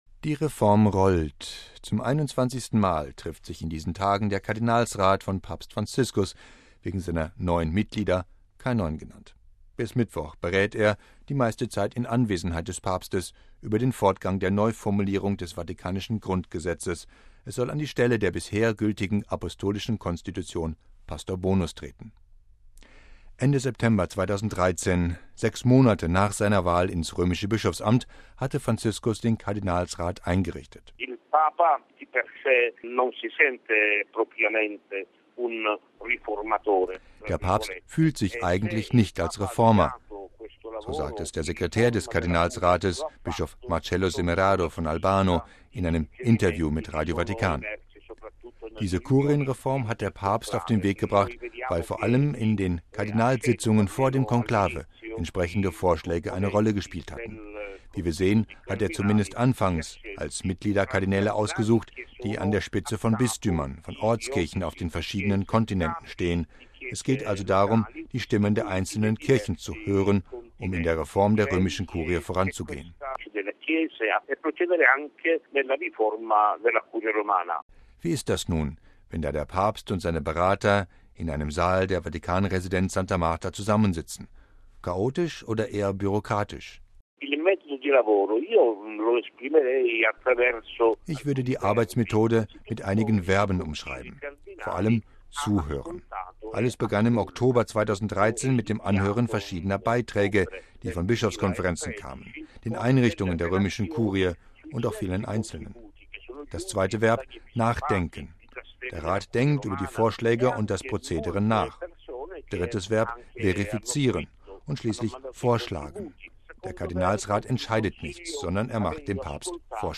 „Der Papst fühlt sich eigentlich nicht als Reformer“, sagt der Sekretär des Kardinalsrates, Bischof Marcello Semeraro von Albano, in einem Interview mit Radio Vatikan.